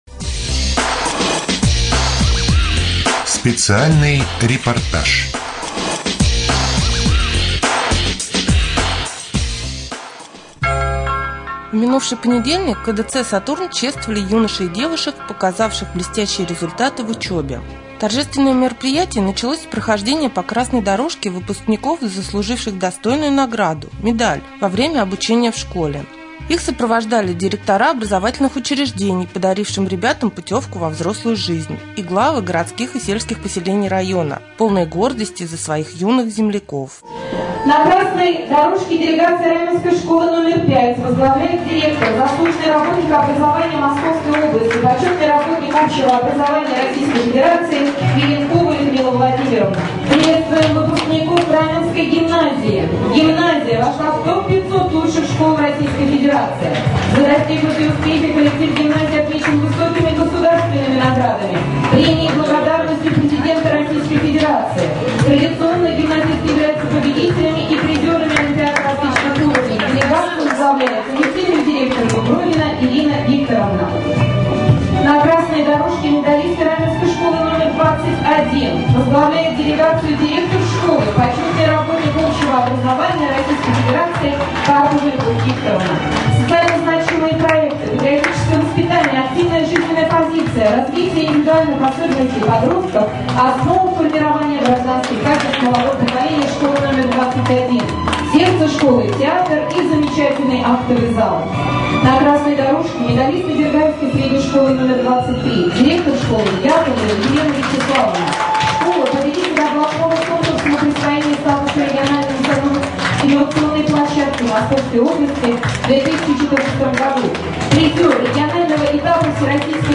1. Рубрика «Специальный репортаж». В минувший понедельник в КДЦ «Сатурн» чествовали юношей и девушек, показавших блестящие результаты в учебе.